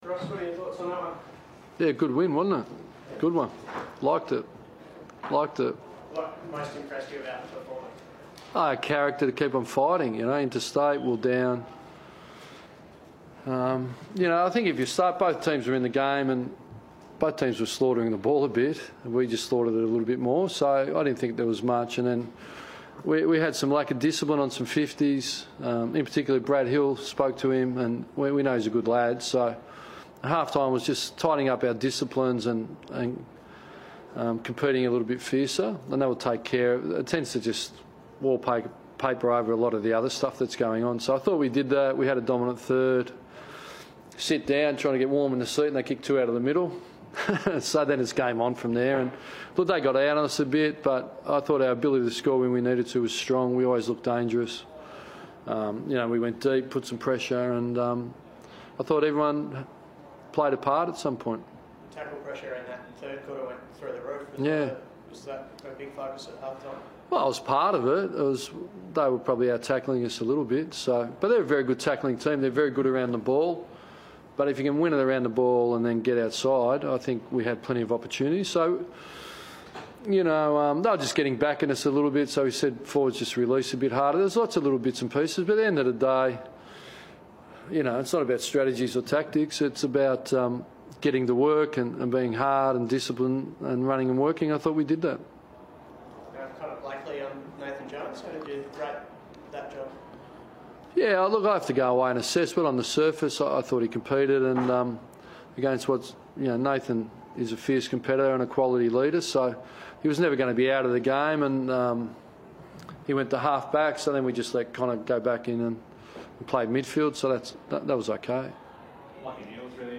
Ross Lyon spoke to the media following the win over Mebourne